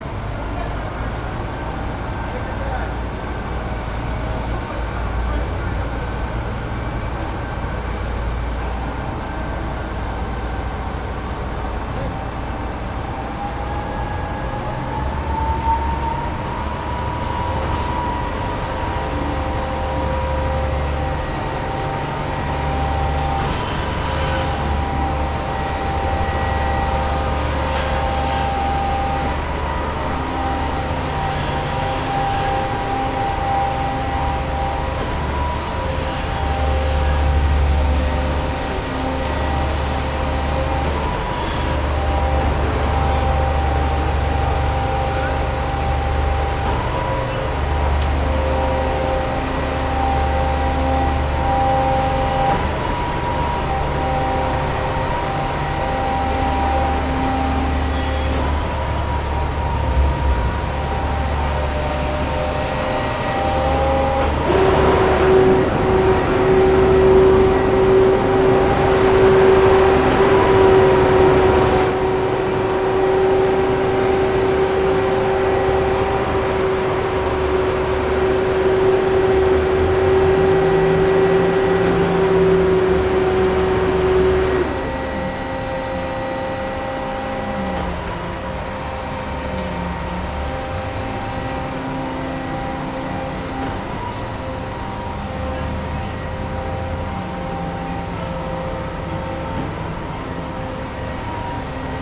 Качество записей проверено – никаких лишних шумов, только чистый звук техники.
Звуки цементовоза на стройке во время выгрузки